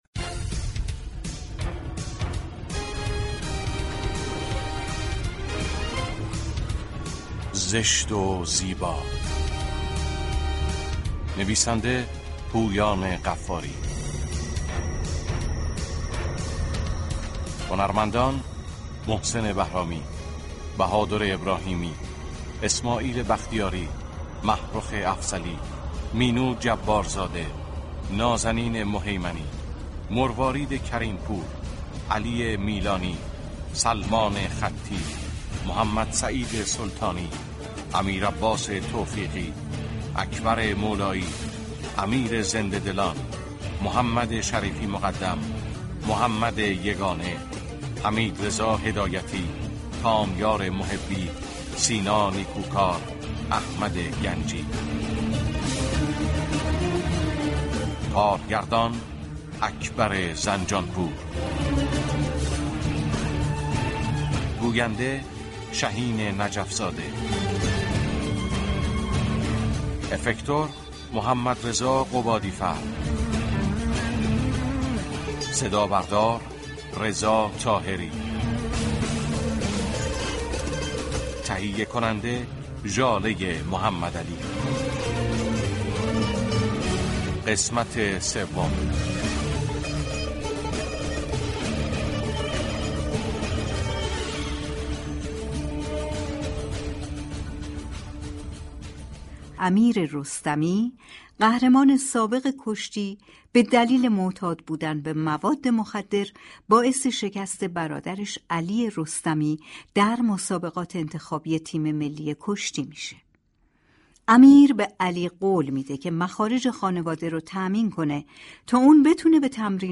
دوازدهم آذر ماه ، شنونده نمایش رادیویی